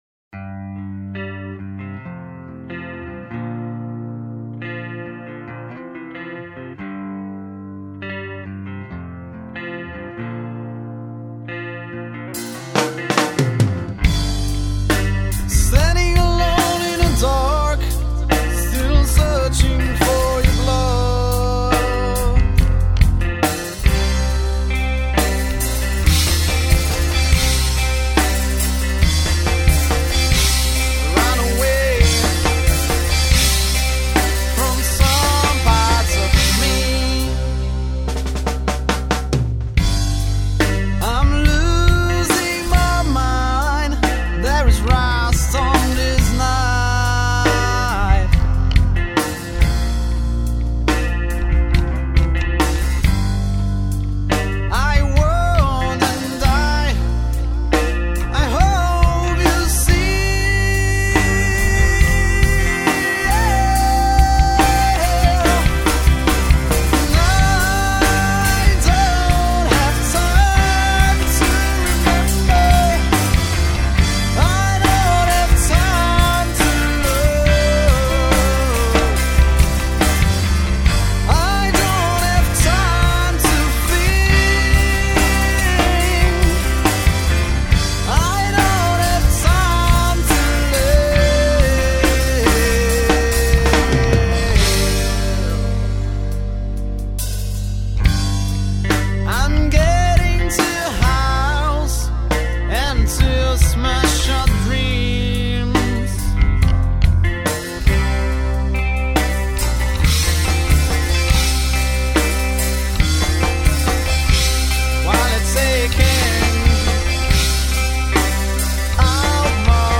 Gatunek: Rock
Wokal
Bass
Perkusja